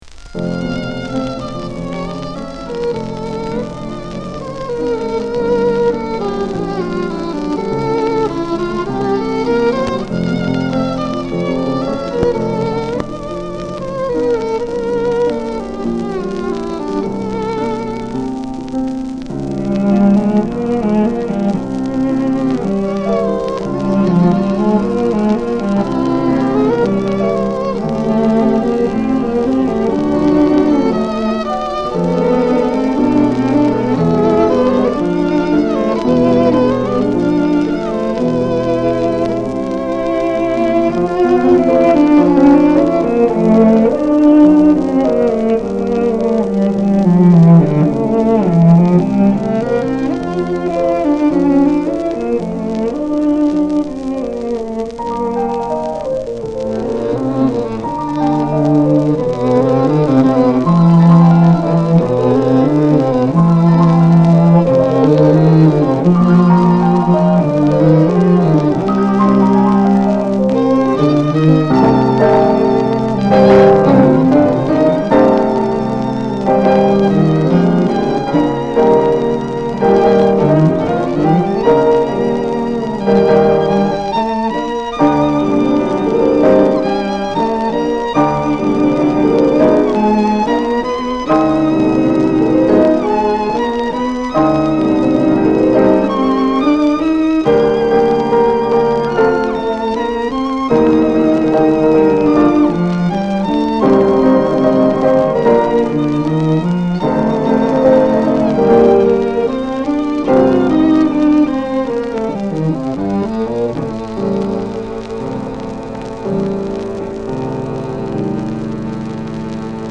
Vintage DIY audio articles & audiofile shellac and vinyl remastering
А.Б. Гольденвейзер, Ф-но, Д.М. Цыганов - скрипка, С.П. Ширинский - виолончель